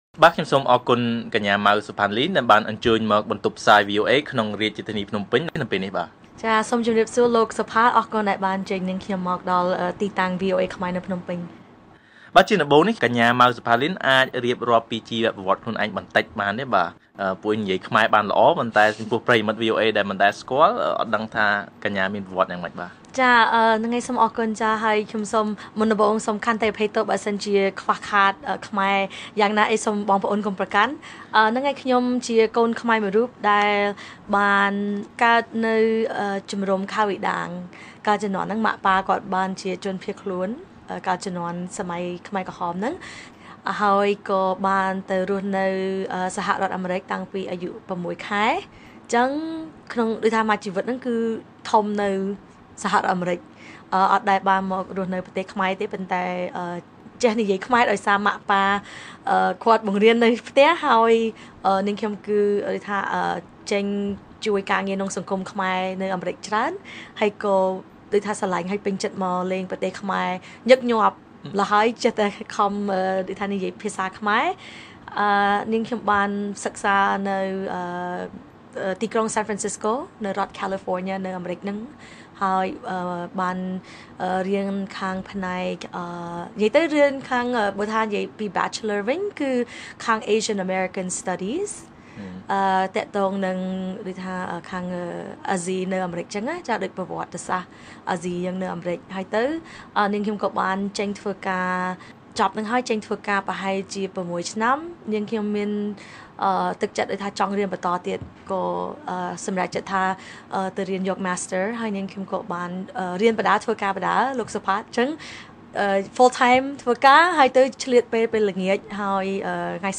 បទសម្ភាសន៍ VOA៖ ស្ត្រីខ្មែរអាមេរិកាំងថាជំនាញ soft skills និងភាសាអង់គ្លេសជាគន្លឹះសំខាន់សម្រាប់ការអប់រំបច្ចេកវិទ្យានៅកម្ពុជា